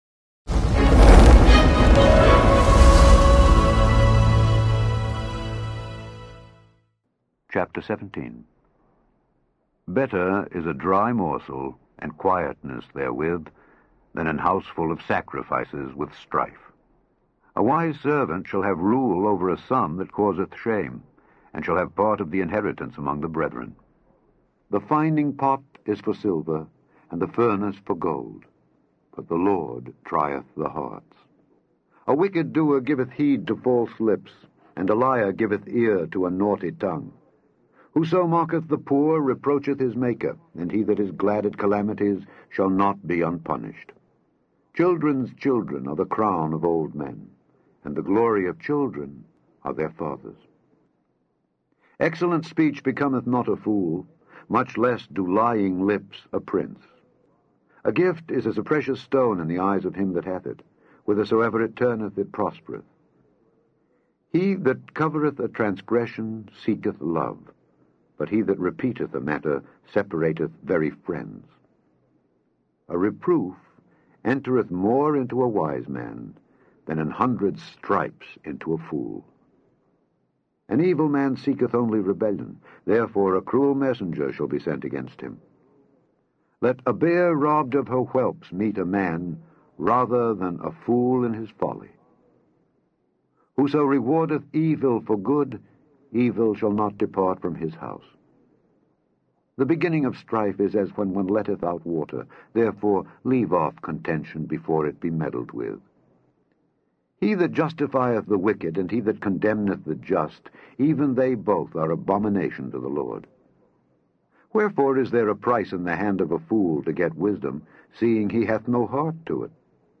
Daily Bible Reading: Proverbs 17-18
In this podcast, you can listen to Alexander Scourby read Proverbs 17-18.